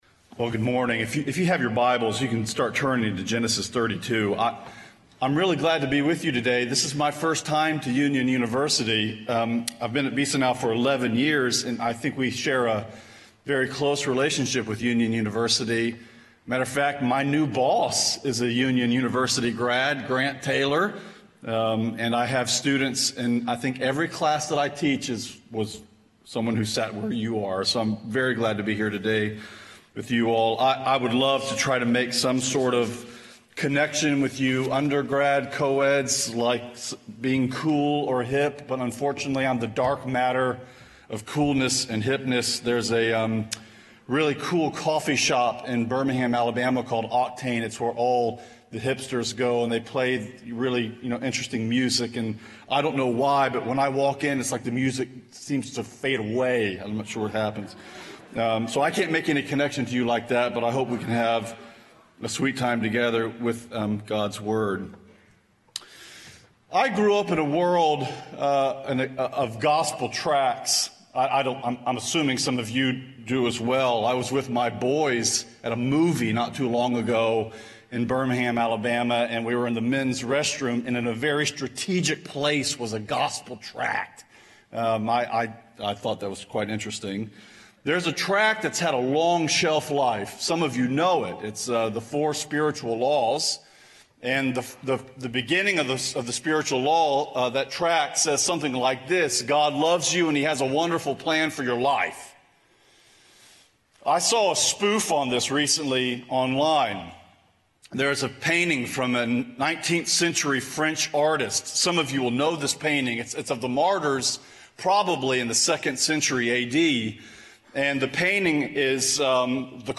Chapel
Address: "Midnight Meeting" from Genesis 32:22-32 Recording Date: Sep 30, 2015, 10:00 a.m. Length: 23:58 Format(s): MP3 ; Listen Now Chapels Podcast Subscribe via XML